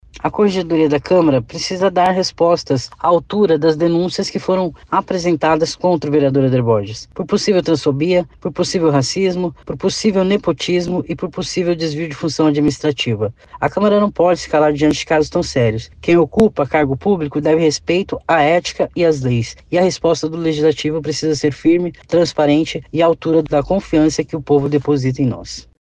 A parlamentar disse que espera uma resposta da Corregedoria da Câmara Municipal para todas as denúncias apresentadas.